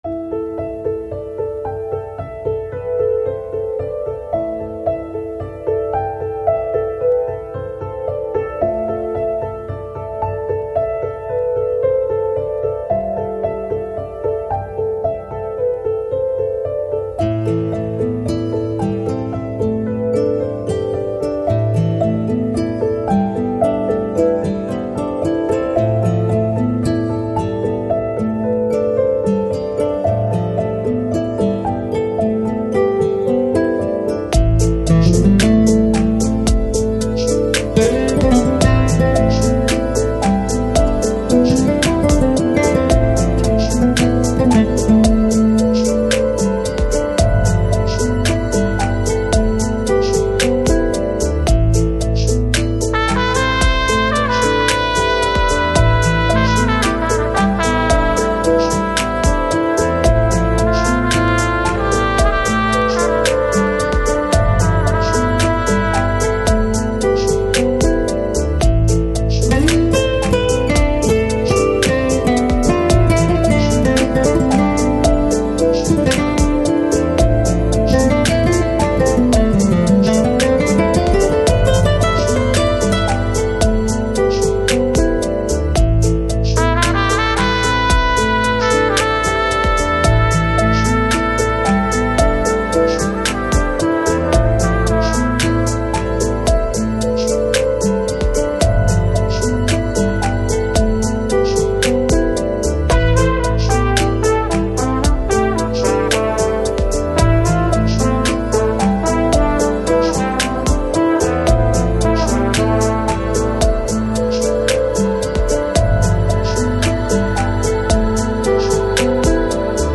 Жанр: Lounge, Chill Out